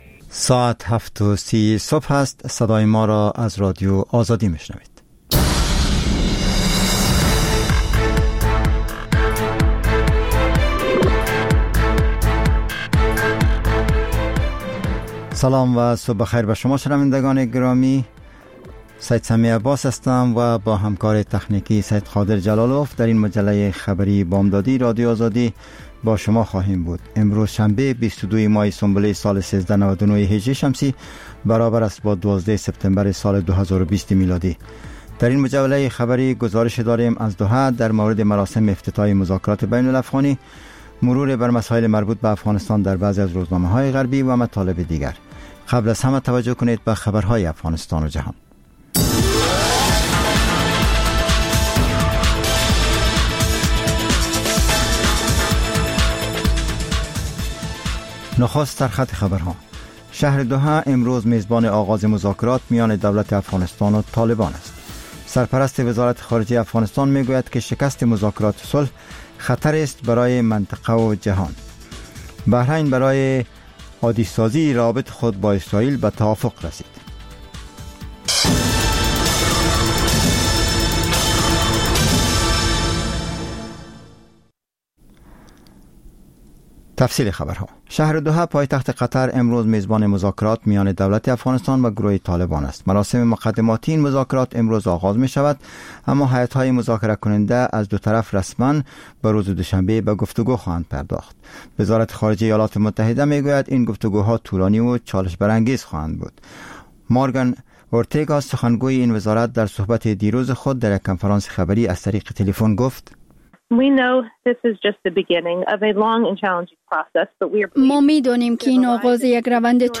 مجله خبری صبح‌گاهی